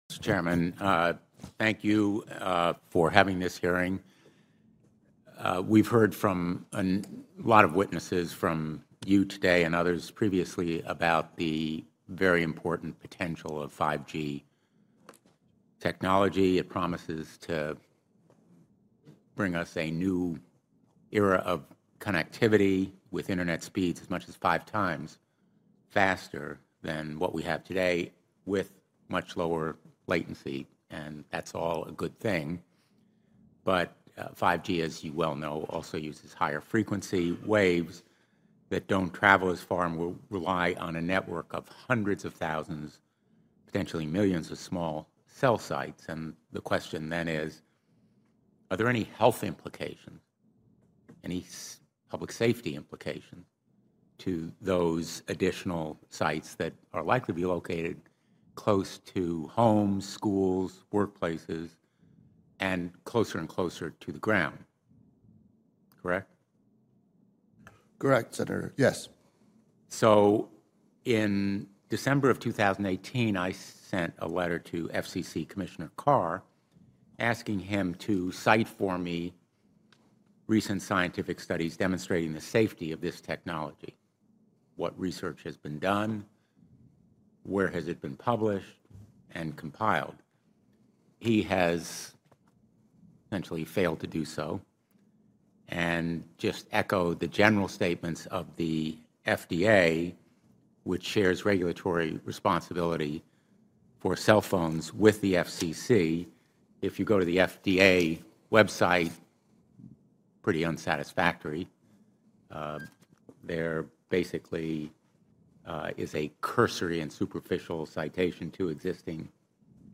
US Senator Blumenthal Raises Concerns on 5G Wireless Technology Health Risks at Senate Hearing